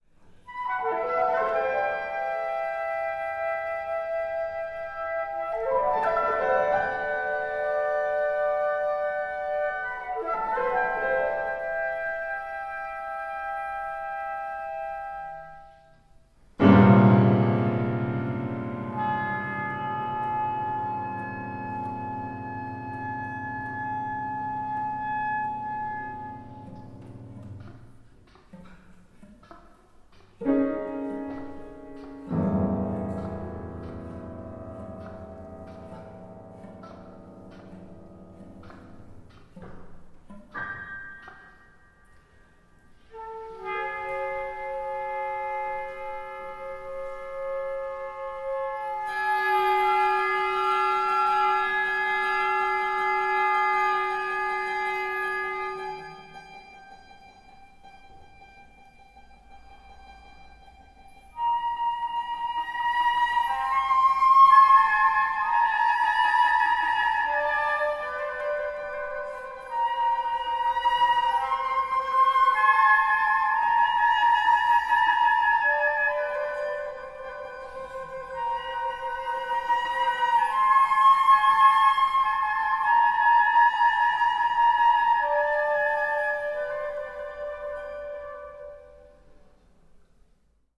Frauta, Oboe, Piano